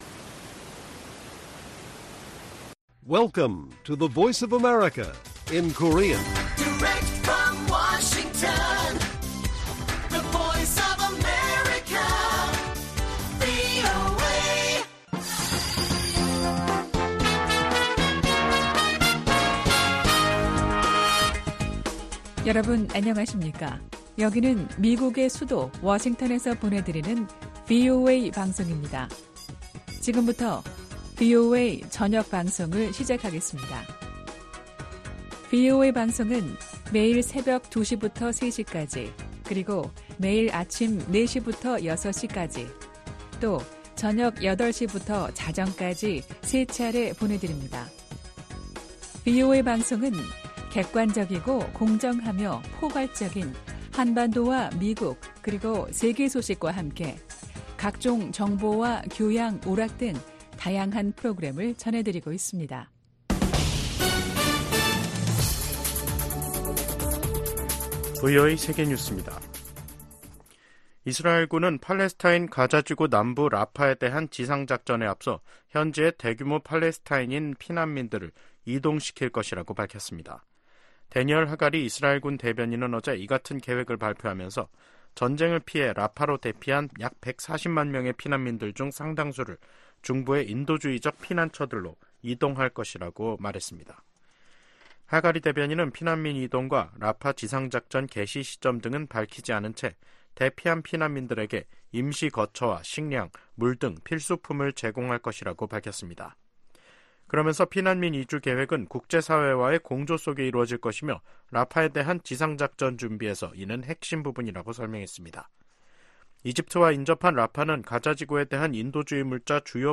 VOA 한국어 간판 뉴스 프로그램 '뉴스 투데이', 2024년 3월 14일 1부 방송입니다. 북한이 자체 핵우산을 가지고 있다는 블라디미르 푸틴 러시아 대통령의 발언에 관해, 미 국무부가 북-러 협력 강화에 우려를 나타냈습니다. 김정은 북한 국무위원장이 신형 탱크를 동원한 훈련을 현지 지도하면서 또 다시 전쟁 준비 완성을 강조했습니다. 미 의회 내 중국위원회가 북한 노동자를 고용한 중국 기업 제품 수입 즉각 중단을 행정부에 촉구했습니다.